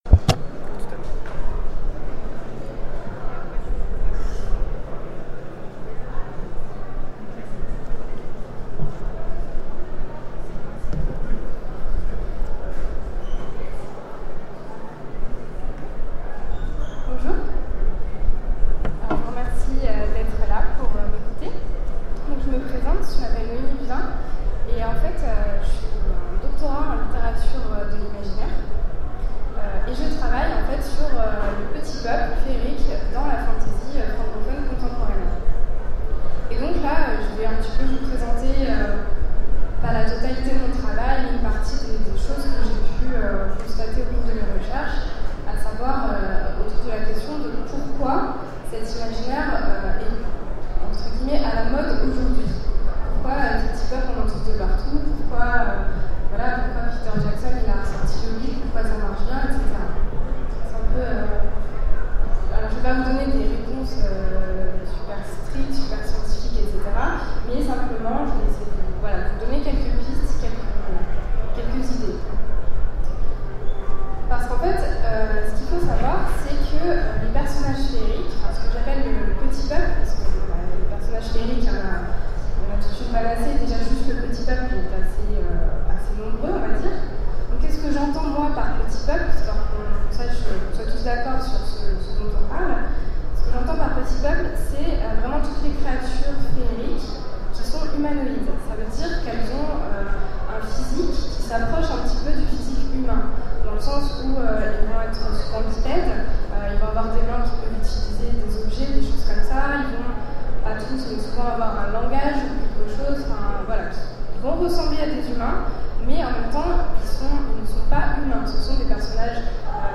Mots-clés fantasy animalière Conférence Partager cet article